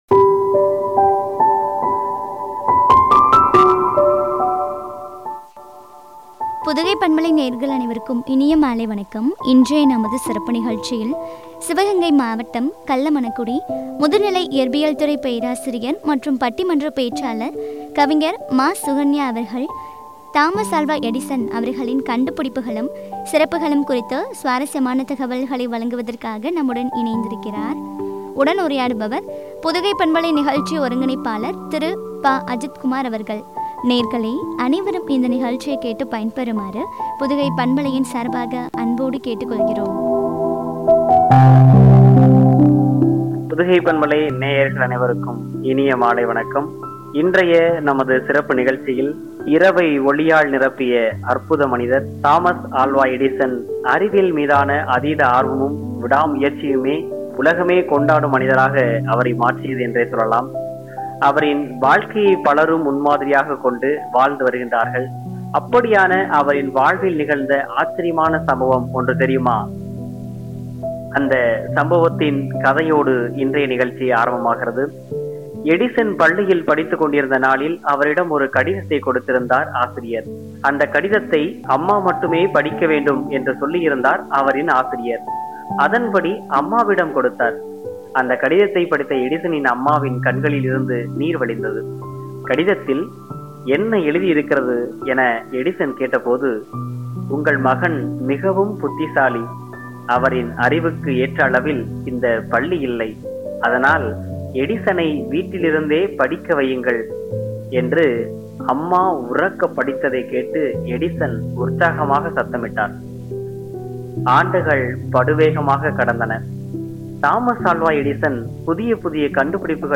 சிறப்புகளும் குறித்து வழங்கிய உரையாடல்.